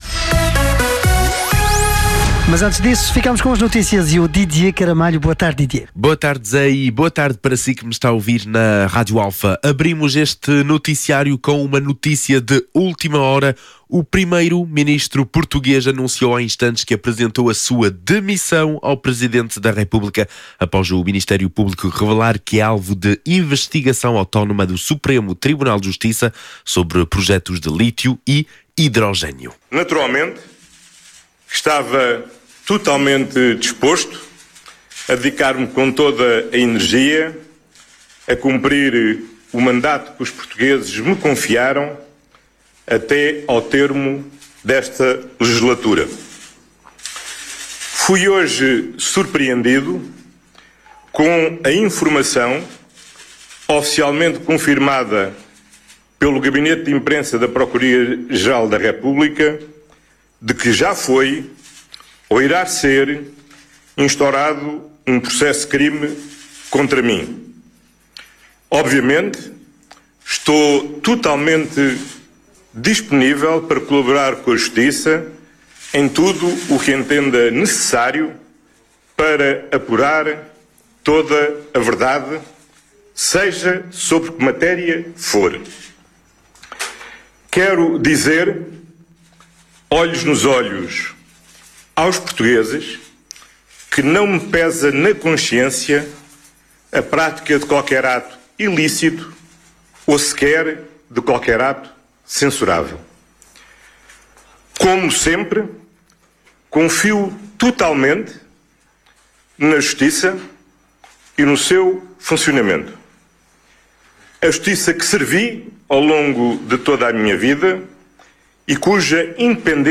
Numa comunicação ao país, a partir da sua residência oficial, em S. Bento, Lisboa, o primeiro-ministro justificou a sua decisão afirmando que “as funções de primeiro-ministro não são compatíveis com a suspeita de qualquer ato criminal”.